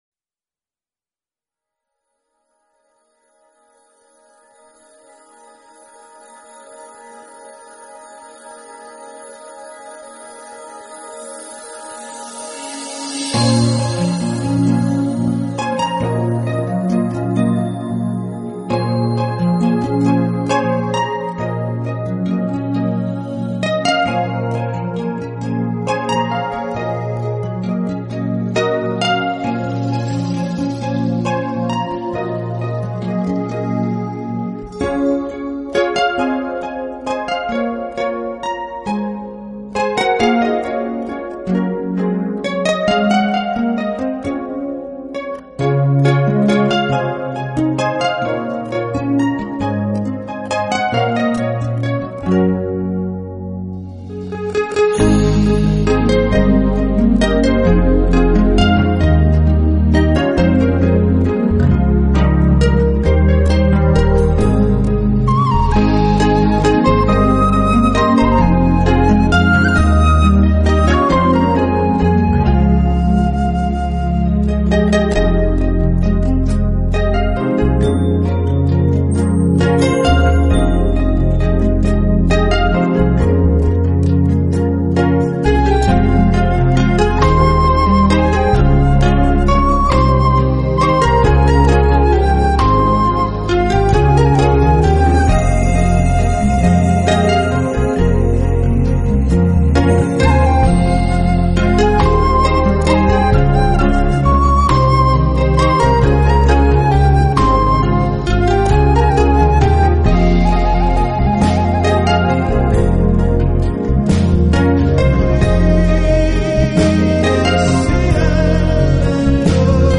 Genre: Instrumental, New Age